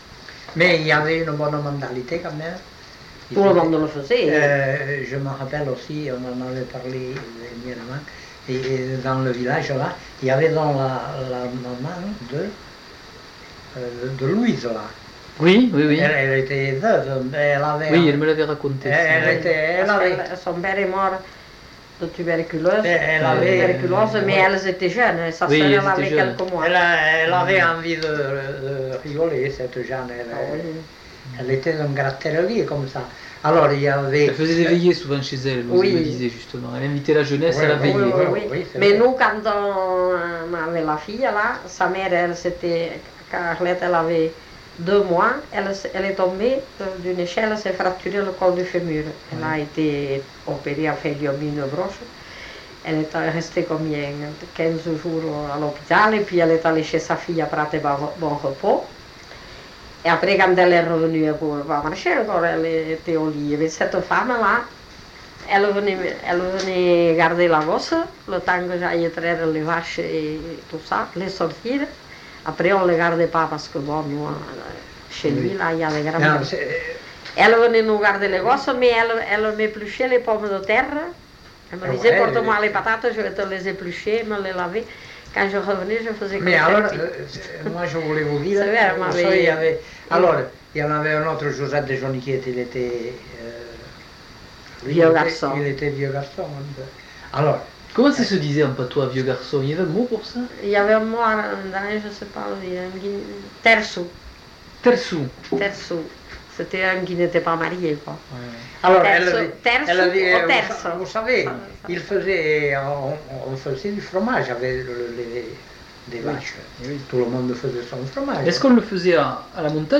Lieu : Eylie (lieu-dit)
Genre : témoignage thématique